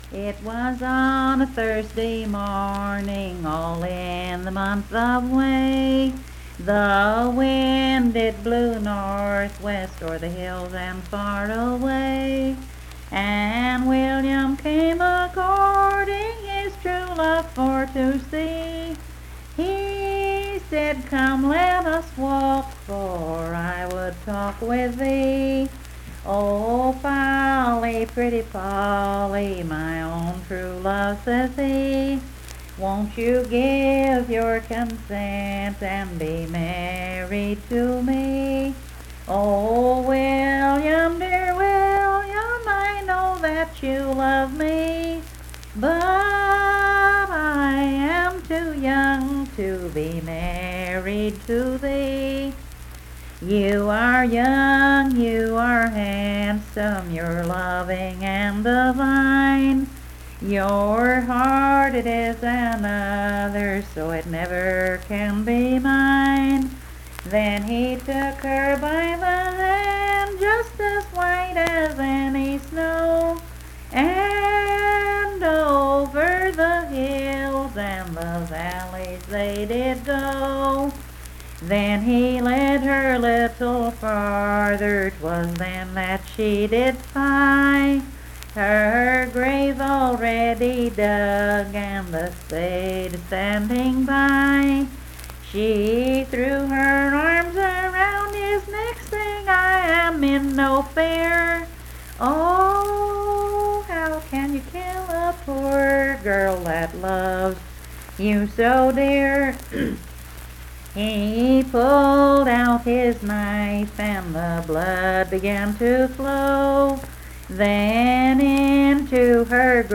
Unaccompanied vocal music
Verse-refrain 7(4).
Performed in Coalfax, Marion County, WV.
Voice (sung)